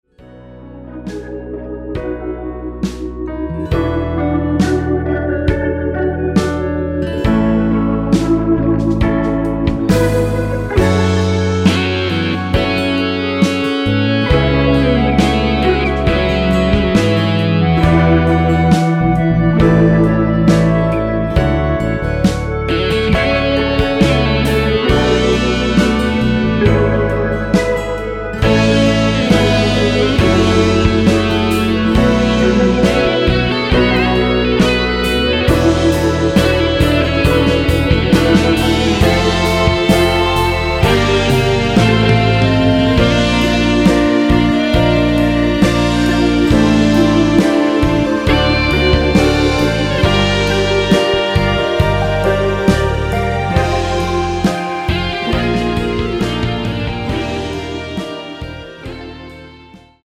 1절 “우리 유일한 대화일지 몰라” 다음 두번째 “한걸음 이제 한걸음일 뿐”으로 진행되게 편곡 되었습니다.
원키에서(-6)내린 1절후 후렴으로 진행 되는 멜로디 포함된 MR입니다.(본문의 가사 부분 참조)
앞부분30초, 뒷부분30초씩 편집해서 올려 드리고 있습니다.
중간에 음이 끈어지고 다시 나오는 이유는